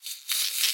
Minecraft Version Minecraft Version latest Latest Release | Latest Snapshot latest / assets / minecraft / sounds / mob / silverfish / say2.ogg Compare With Compare With Latest Release | Latest Snapshot